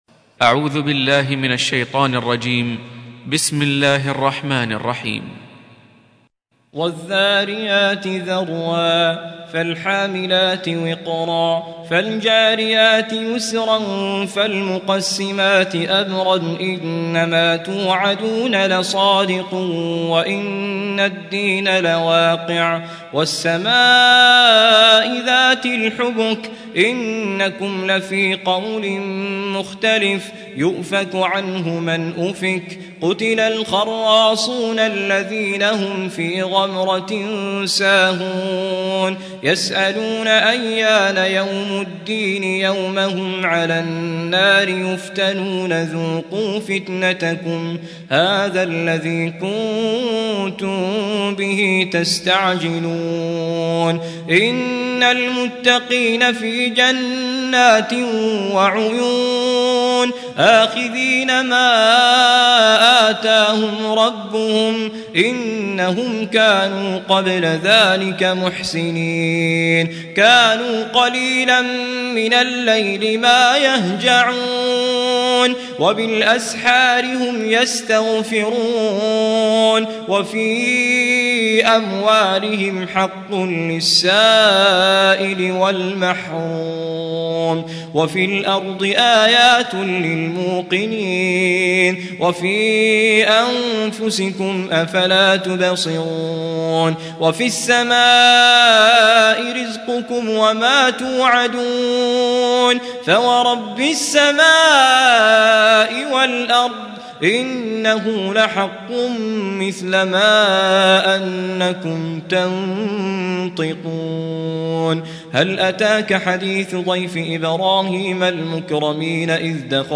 Surah Sequence تتابع السورة Download Surah حمّل السورة Reciting Murattalah Audio for 51. Surah Az-Z�riy�t سورة الذاريات N.B *Surah Includes Al-Basmalah Reciters Sequents تتابع التلاوات Reciters Repeats تكرار التلاوات